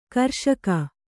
♪ karṣaka